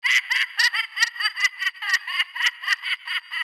SYNTHLAUGH.wav